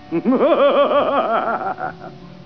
Geräusche:   m e n s c h l i c h
Lachen (männl.) 27 2
laughing7.wav